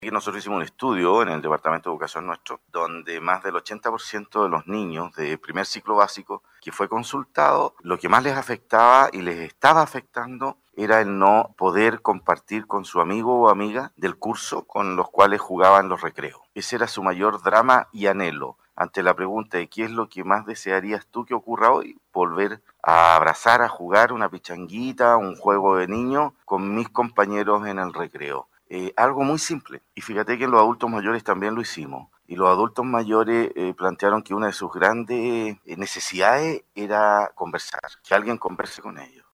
En una nueva edición del programa conversando en tu comuna de Radio Sago, esta vez desde la ciudad de Los Muermos, el alcalde Emilio González, se refirió respecto a la actualidad de cómo han enfrentado y han vivido en medio de la pandemia por coronavirus, indicando que a través de su departamento de Educación Municipal, realizaron un sondeo comunal con escolares para obtener información de cómo ha sido para ellos esta suspensión de clases, lo que arrojó que en su mayoría de los niños manifestaron afectación emocional por no poder compartir con sus compañeros de colegio.